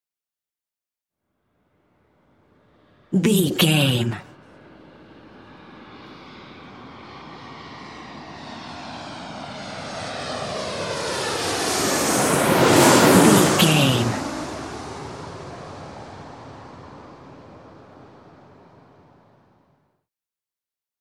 Airplane passby
Sound Effects